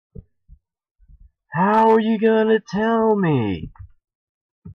Tags: Freedom. DEEP. Nice